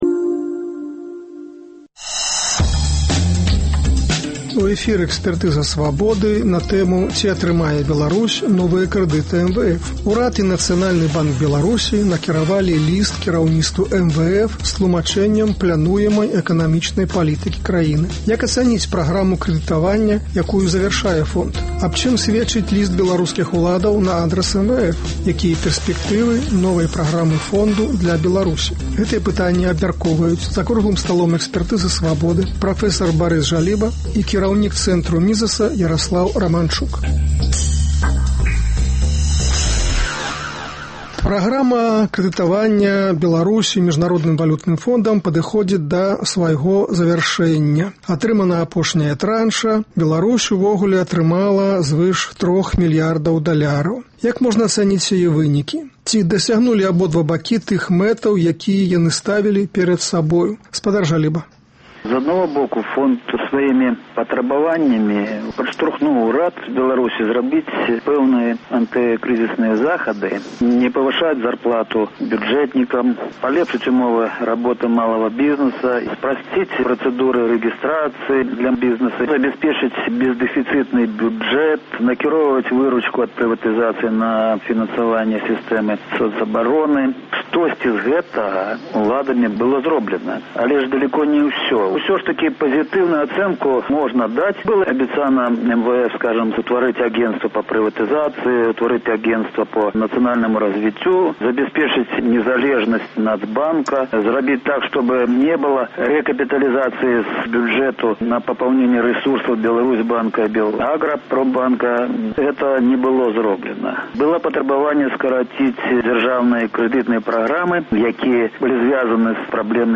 Якія пэрспэктывы новай праграмы фонду для Беларусі? Гэтыя пытаньні абмяркоўваюць за круглым сталом